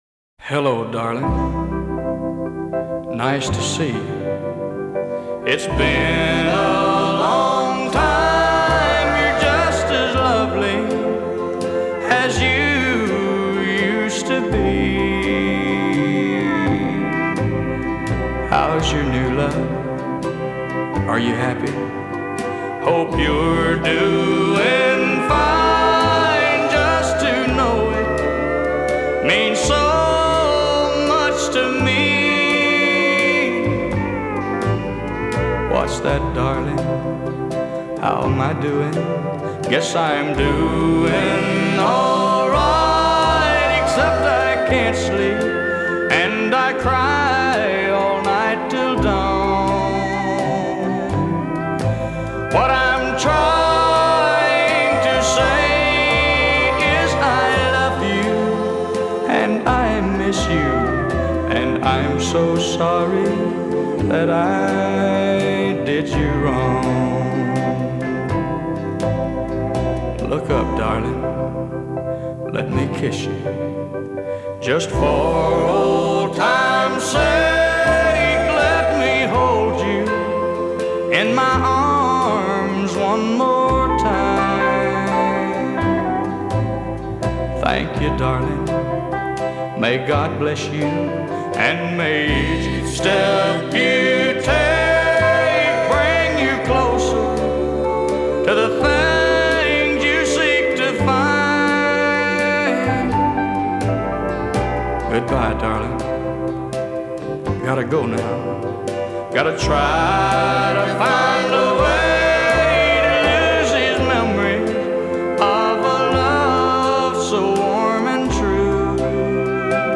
Style: country